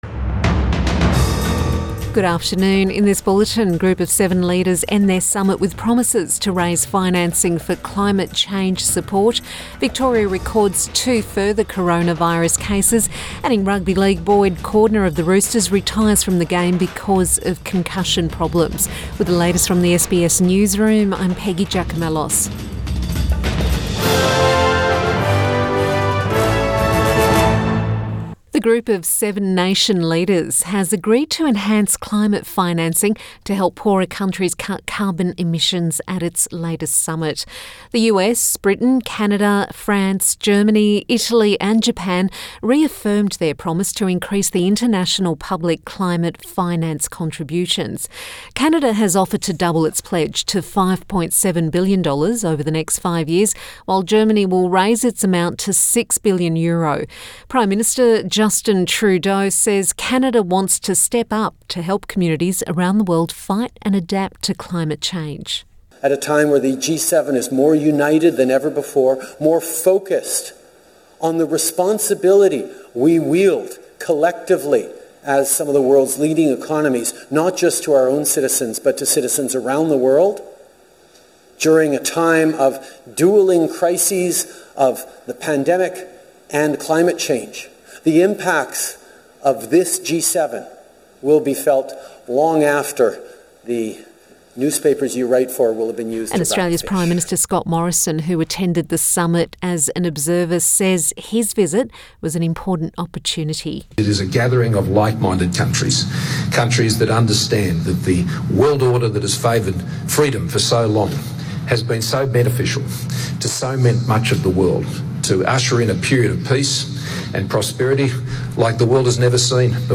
Midday bulletin 14 June 2021